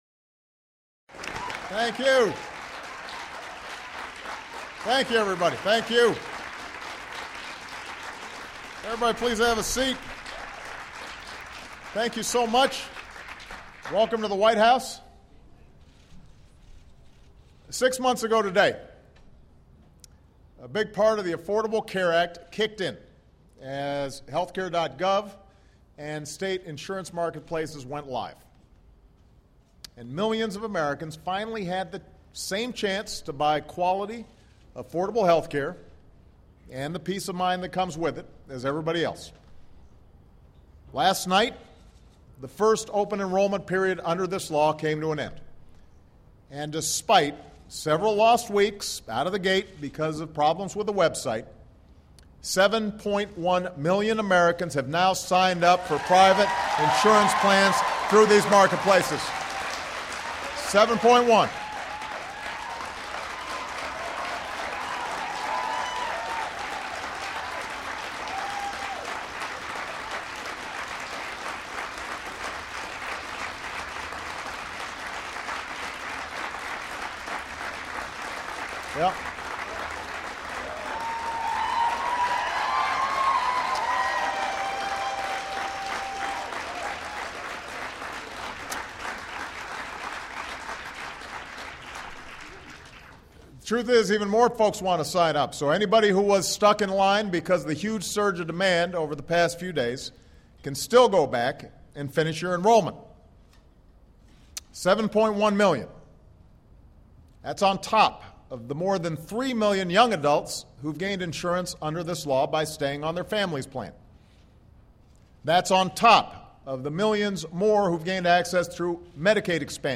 Obama says the number of people covered is up and costs are down. He also says providing coverage for everyone in the country is achievable. Held in the White House Rose Garden.